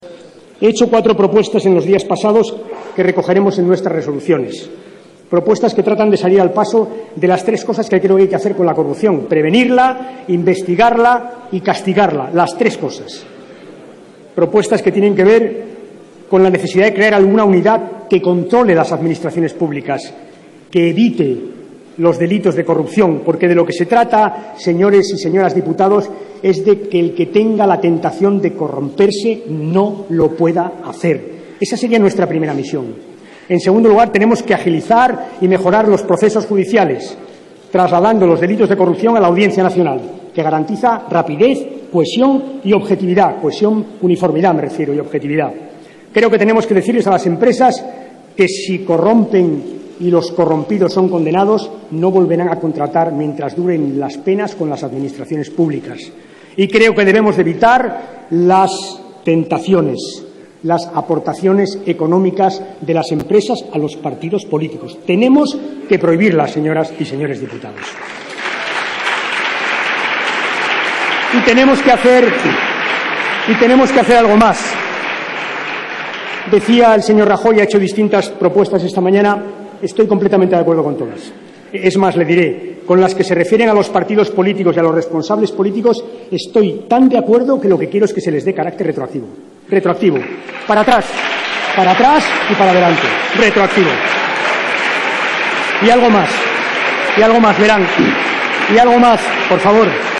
Alfredo P. Rubalcaba. Debate del Estado de la Nación 20/02/2013